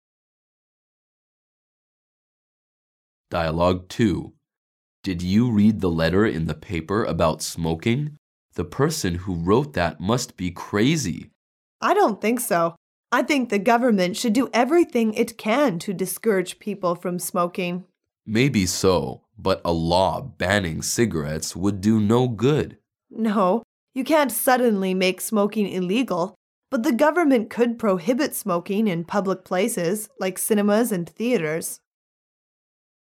Dialouge 2